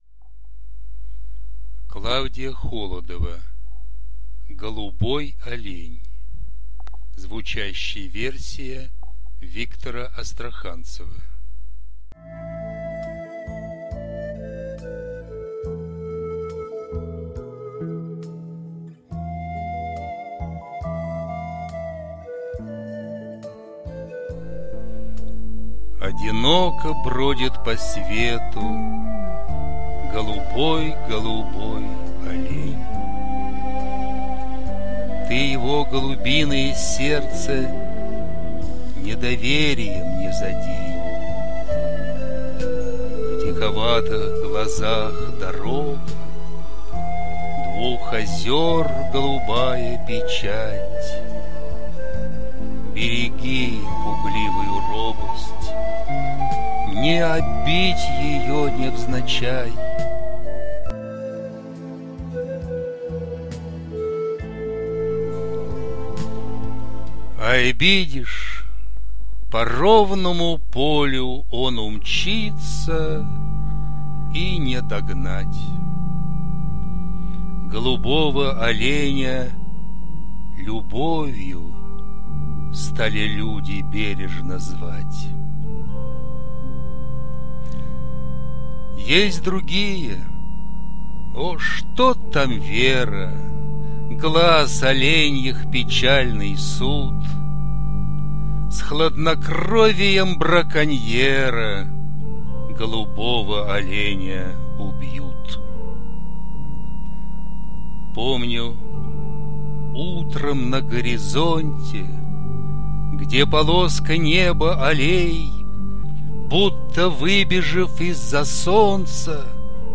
• Жанр: Декламация
Мелодекламация